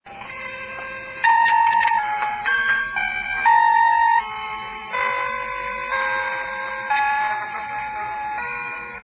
Cette bande contient quelques secondes d'un son qui fait penser aux messages venus de 'l'au-delà '.
bruits_etranges.wav